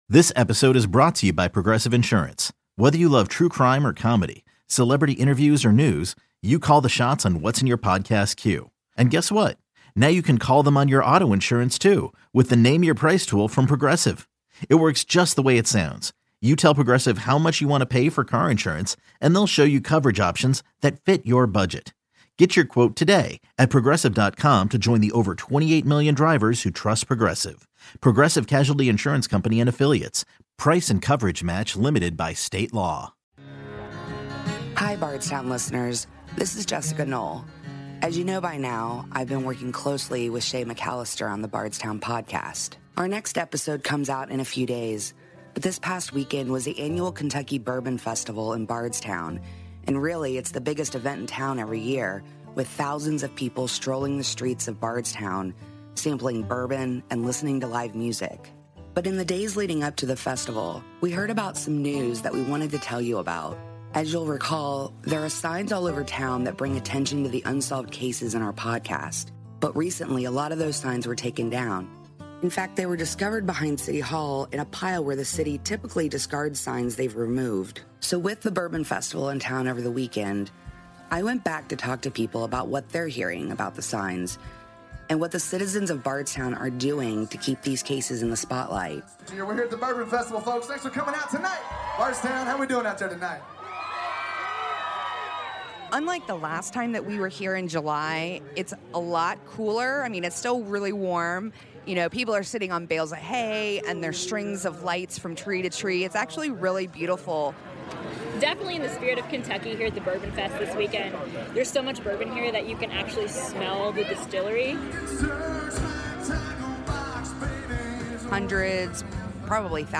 Bardstown: Live Update from the Kentucky Bourbon Festival